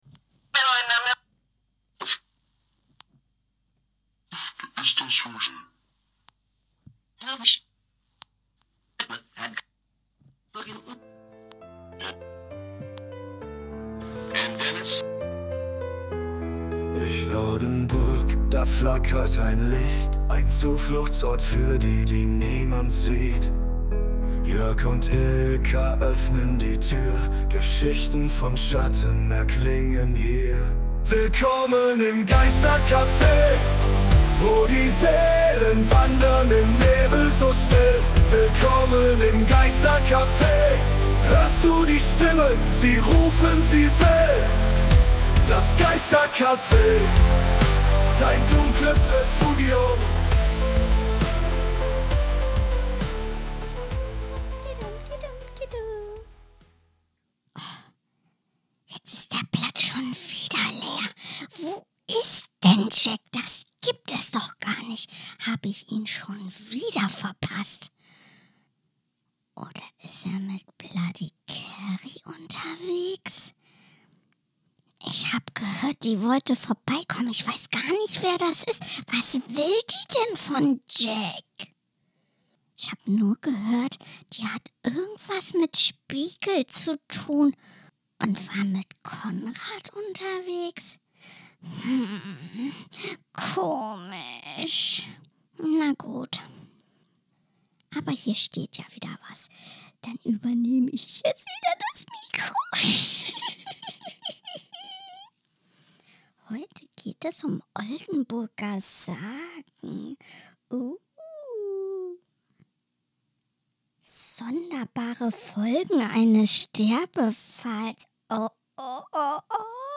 Jack kann Josi nämlich gar nicht leiden – und genau das sorgt immer wieder für spukhafte Stimmung im Café. In der neunten Episode des Spuktober 2025 werfen wir abermals einen Blick auf das, was euch in den kommenden Nächten erwartet, und starten mit einer Geschichte, die euch garantiert das Blut in den Adern gefrieren lässt. Dazu gesellen sich einige unserer Freunde, die den Spuktober mit ihren Stimmen noch lebendiger und gruseliger machen.